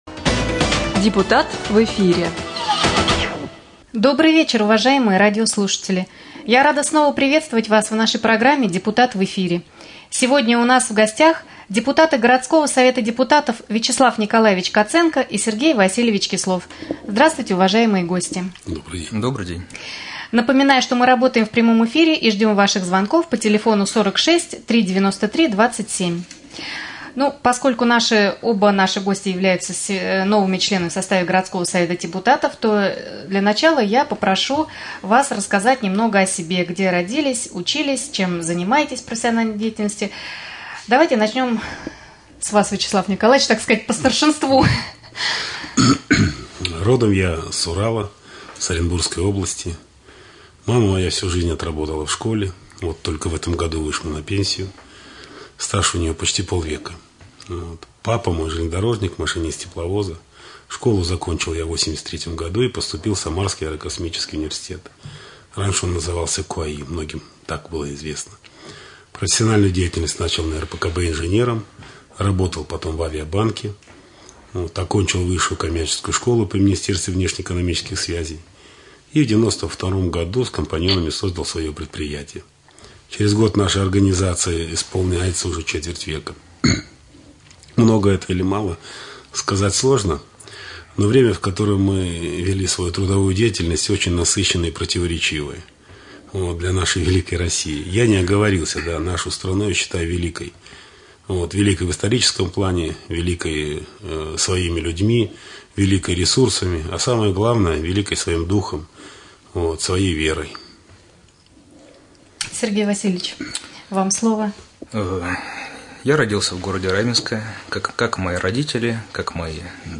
1. В прямом эфире депутаты 2.